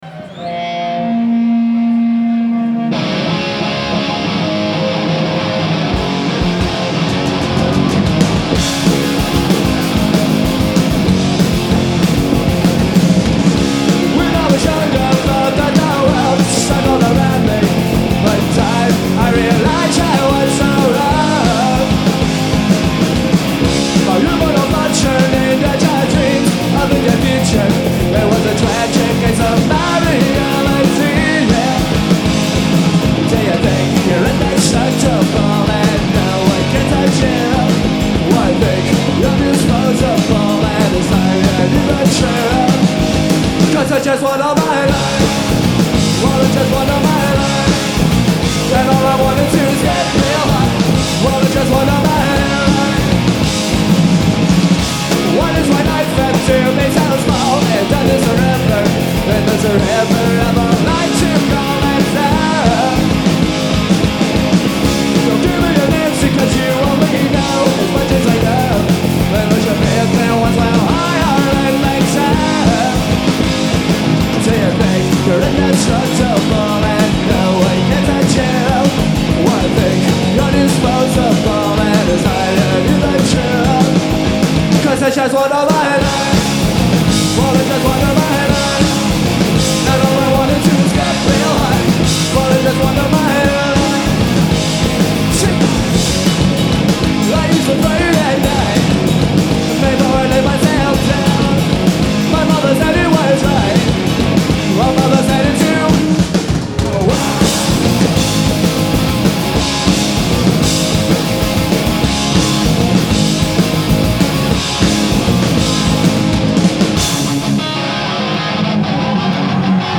Genre : Alternative & Indie
Live at Garatge Club, Barcelona 1994